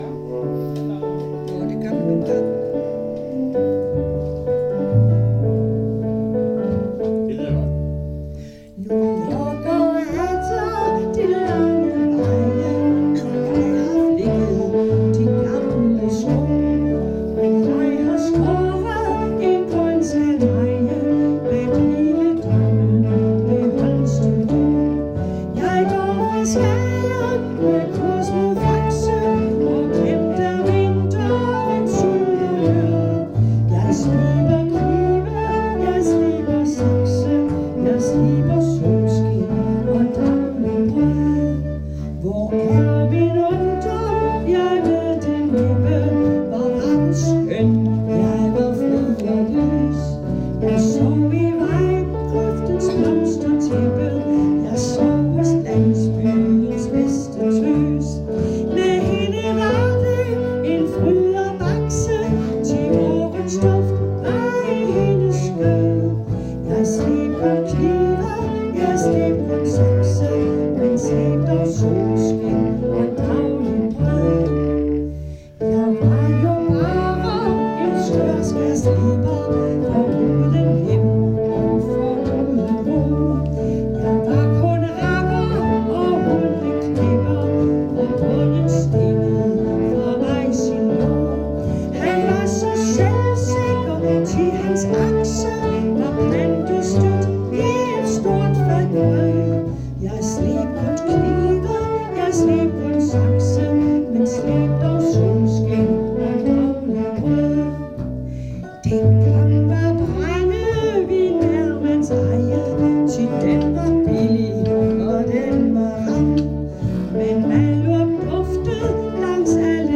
Øveaften 23. oktober 2024: